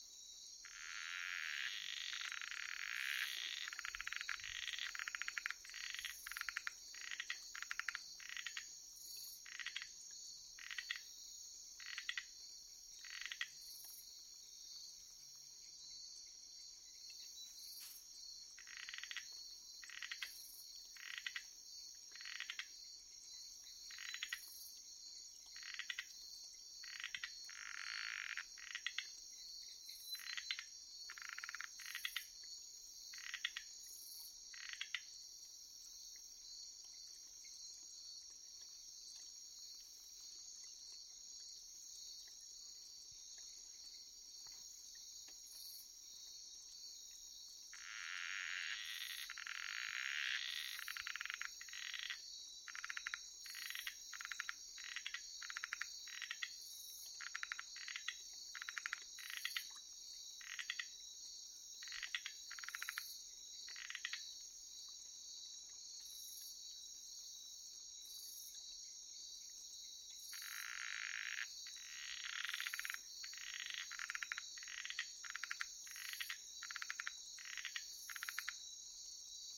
Especie: Osteopilus pulchrilineatus
Clase: Amphibia
Localidad: República Dominicana: Cotuí, Sánchez-Ramirez
Naturaleza: Hábitat natural